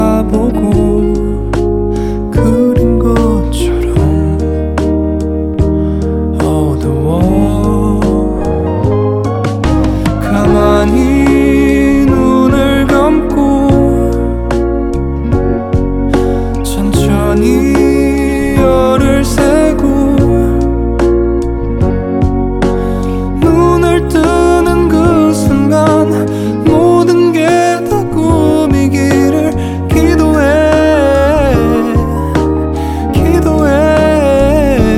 Жанр: Поп / K-pop / Музыка из фильмов / Саундтреки